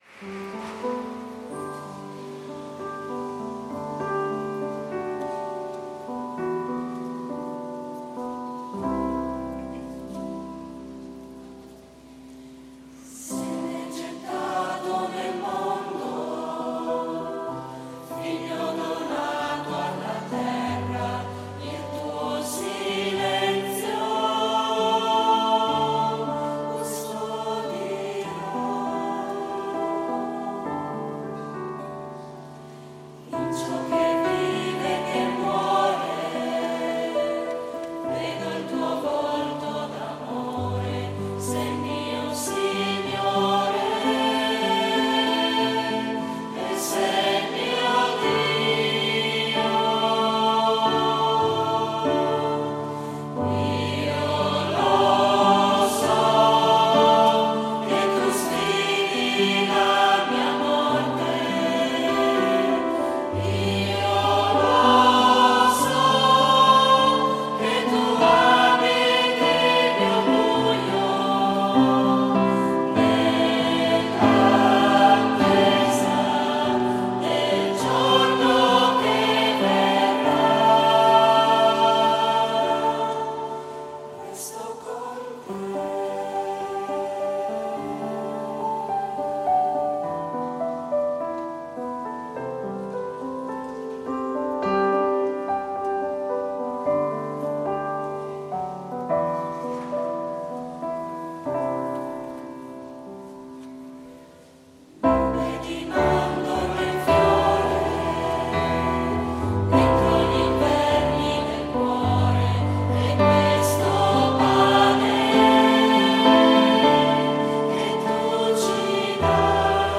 "Resto con te" Coro Giovani.